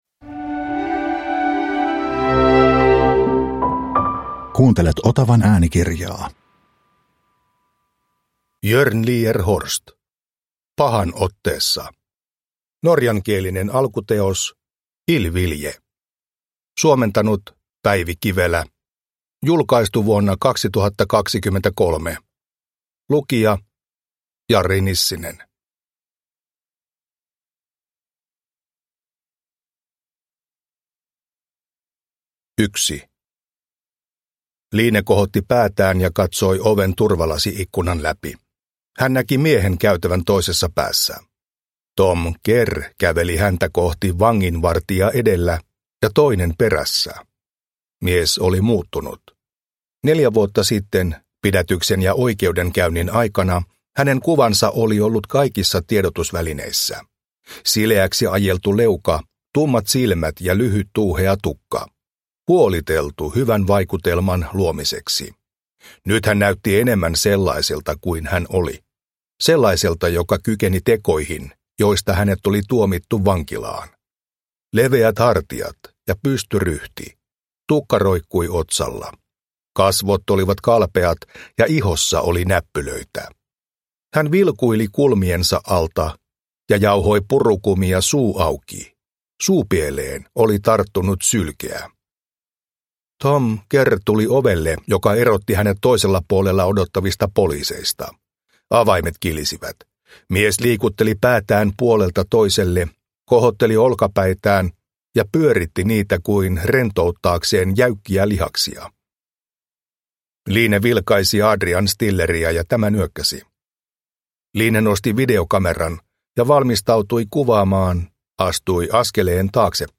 Pahan otteessa – Ljudbok – Laddas ner